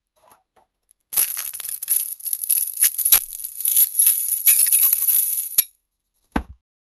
Создай звук открывания железной тяжелой двери в военной тюрьме